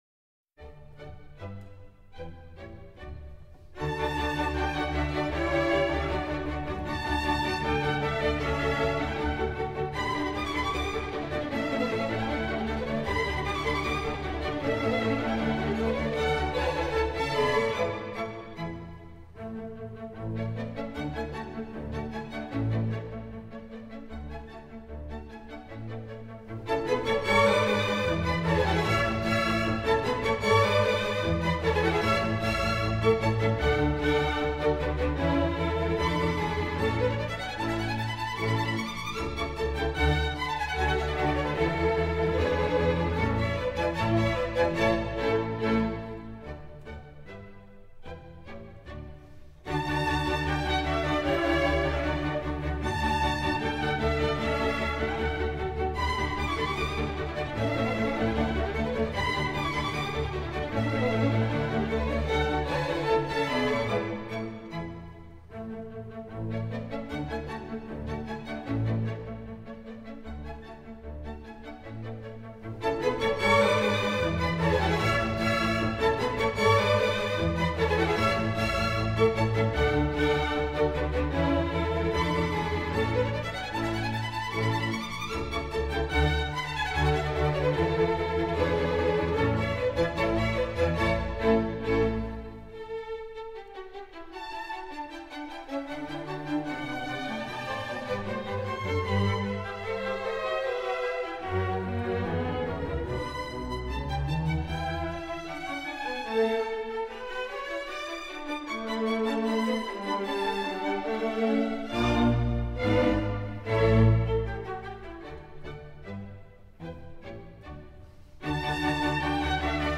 Berliner Philharmoniker, 1988.1.6